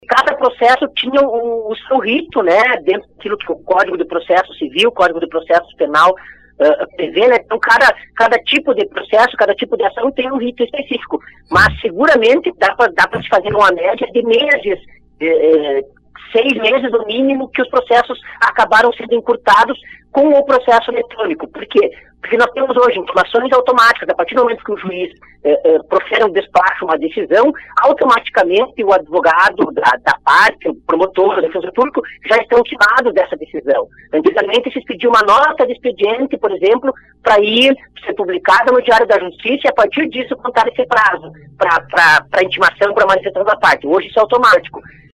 A implantação dos processos eletrônicos tem agilizado o andamento dos trabalhos na justiça. Falando hoje no Fatorama, o juiz Eduardo Giovelli, titular da Vara Criminal de ljuí, disse que com esta modalidade, os prazos tem reduzido com o encaminhamento das decisões.